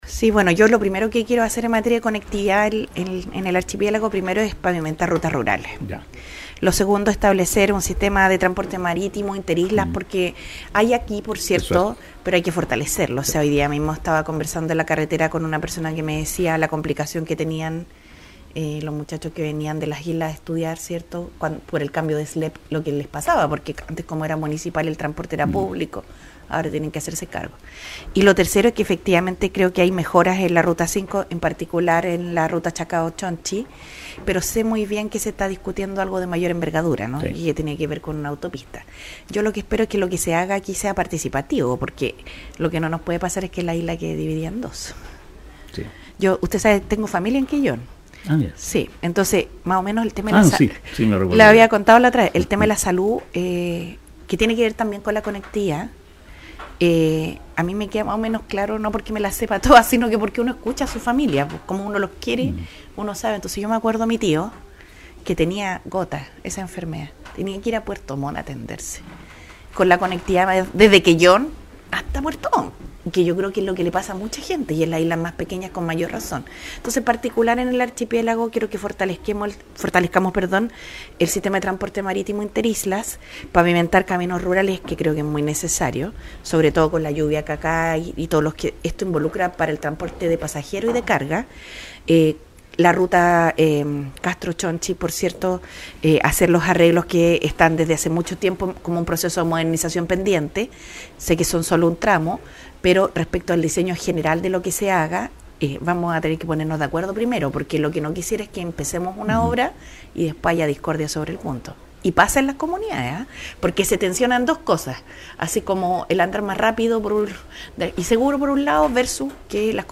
La candidata Jeannette Jara estuvo en la tarde de este miércoles en Radio Chiloé de Castro para comunicar a la comunidad chilota sus planes y programas para el territorio.
Lo hizo antes de acudir al multitudinario acto realizado en la plaza de la capital provincial y en la entrevista concedida al medio castreño la candidata de la centro – izquierda dio cuenta de varias propuestas que tiene para Chiloé.
JEANNETTE-JARA-EN-RADIO-CHILOE.mp3